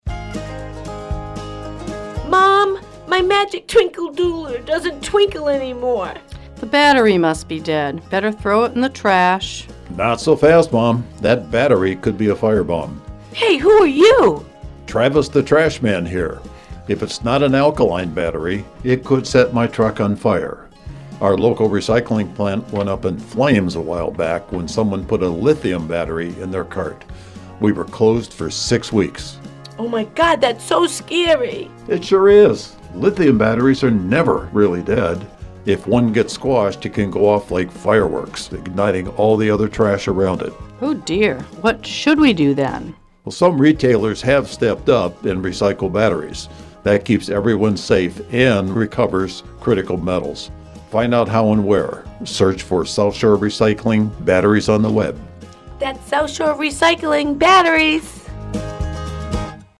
Radio Ads
The South Shore Recycling Cooperative has made numerous public service broadcast announcements on area radio stations to spread the word about recycling, hazardous waste, and related topics.
Special thanks to 95.9 WATD FM for recording and distributing the ads, and providing some free airtime.